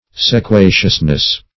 Sequaciousness \Se*qua"cious*ness\, n.
sequaciousness.mp3